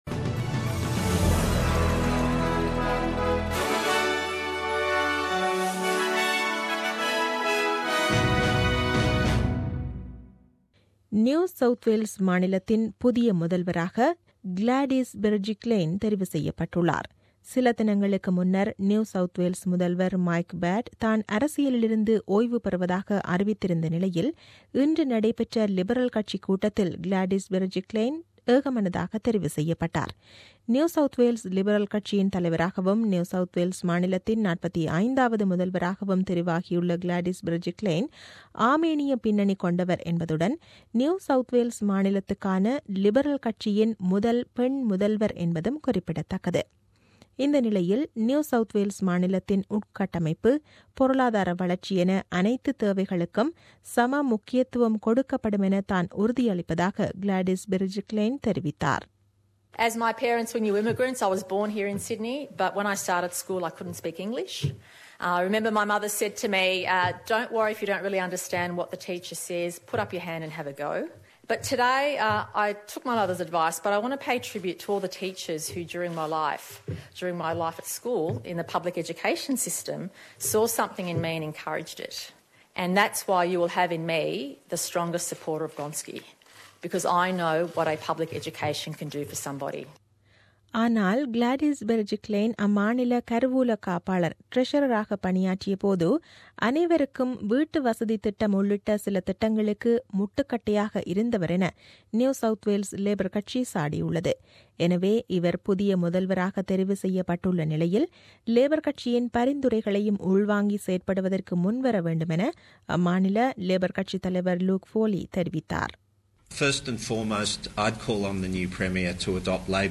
The news bulletin aired on 23 January 2017 at 8pm.